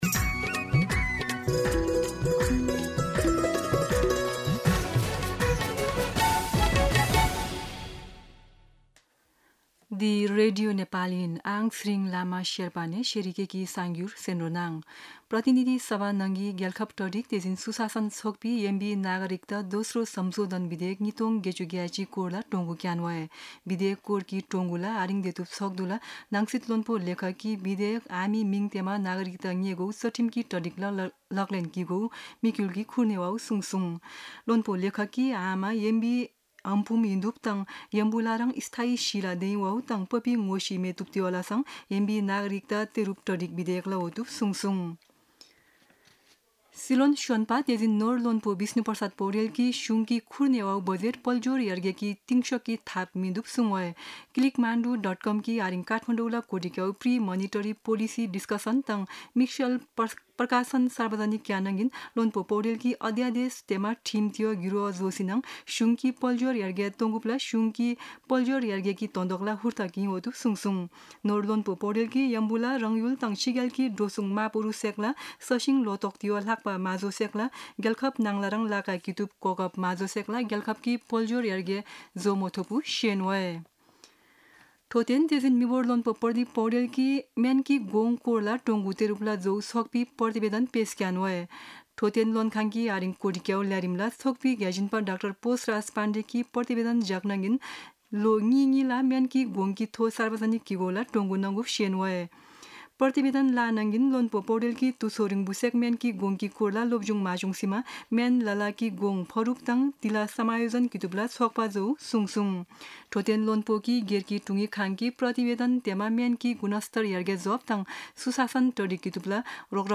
शेर्पा भाषाको समाचार : २७ जेठ , २०८२
Sherpa-News-27.mp3